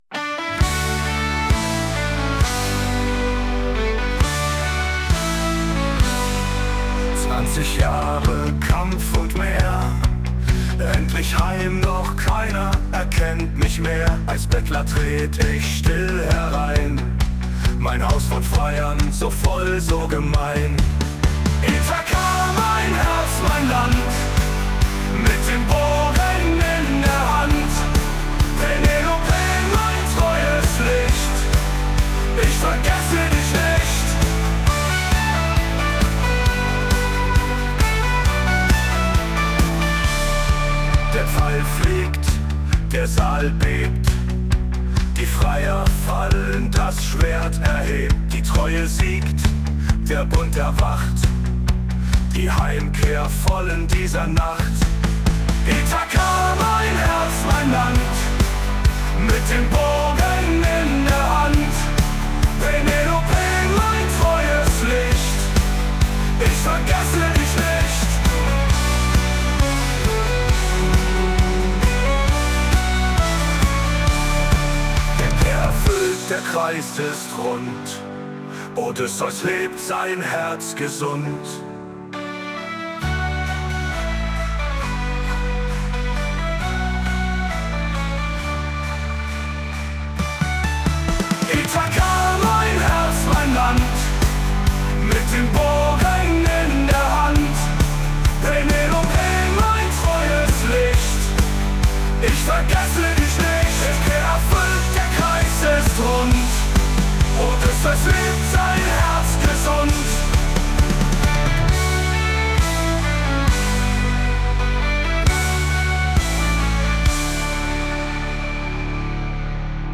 mit ChatGPT getextet und mit SUNO vertont.